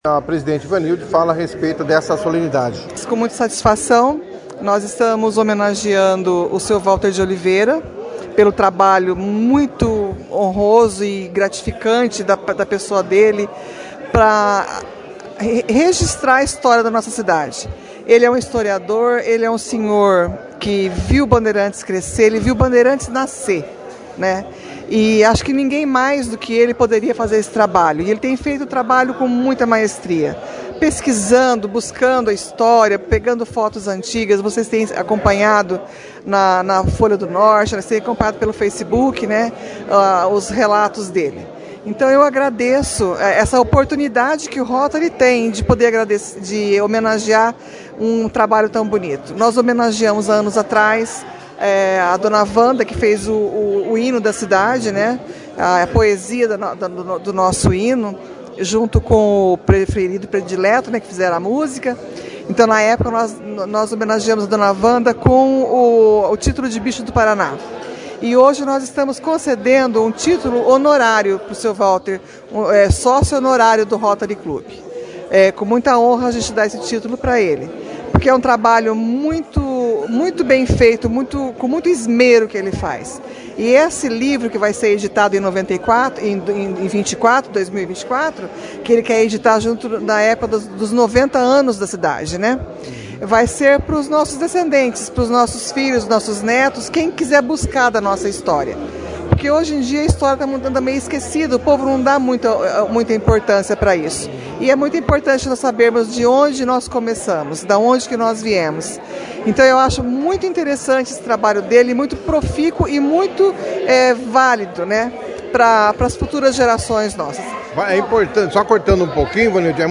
A cerimônia foi destaque na 2ª edição do jornal Operação Cidade